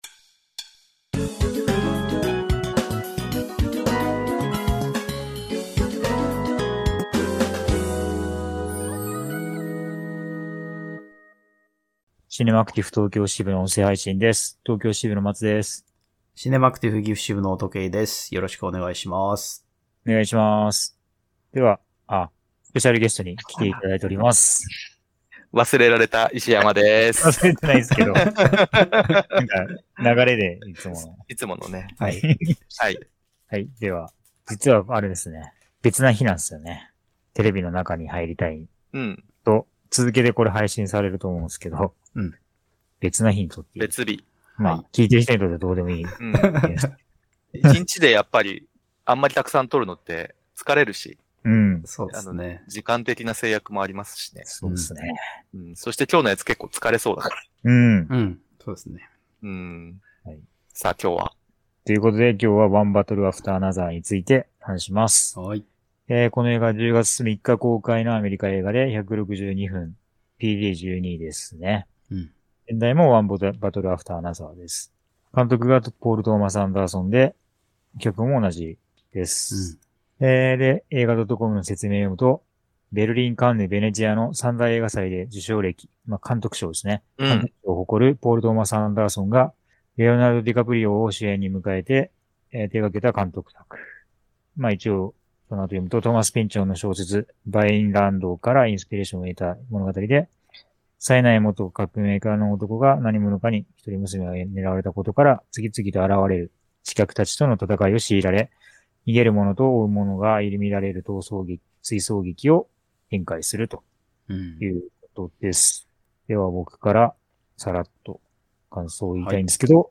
の3人で「ワン・バトル・アフター・アナザー」についてネタバレありで話してます。